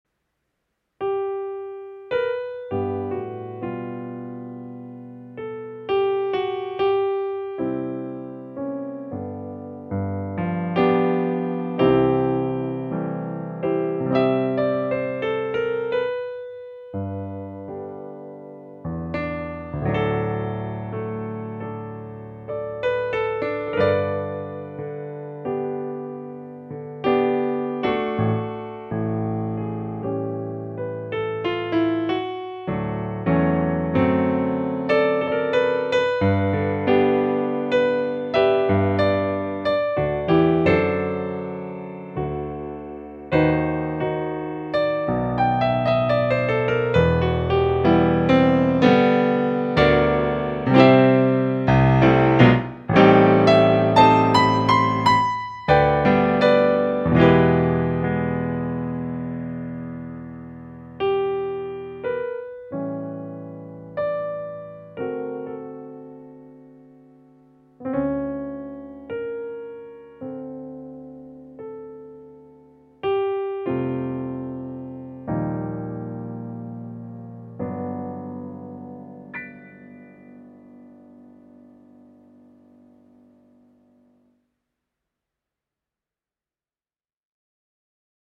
I Need Thee Every Hour (gospel blues
Piano Solo
A single improvised recorded take, it incorporates every gospel-bluesy gesture and technique I knew at the time.
Voicing/Instrumentation: Piano Solo We also have other 68 arrangements of " I Need Thee Every Hour ".